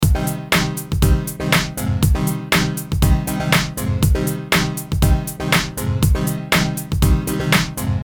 Of course depending on the situation these two sounds could vary drastically but in this case I am using a drum loop to apply some syncopated gain reduction to a synth line.
The two untreated sounds we’ll be using here